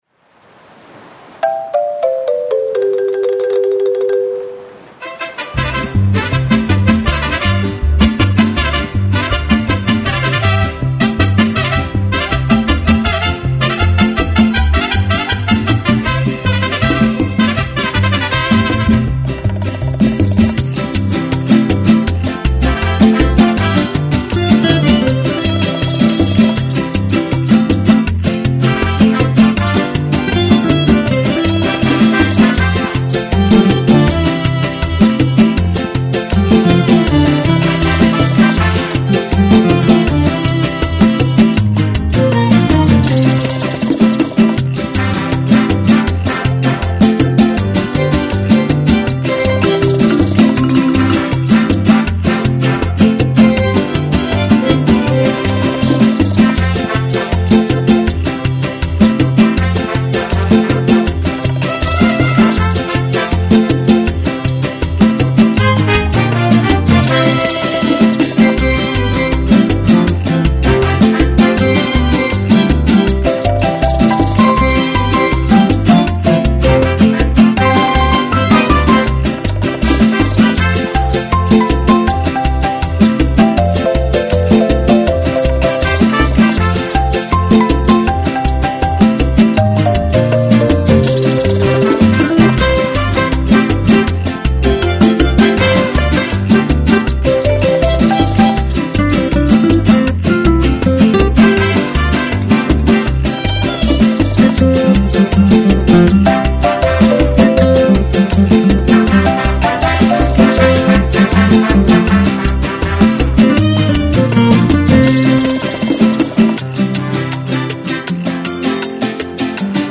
內容類型: Soundtrack。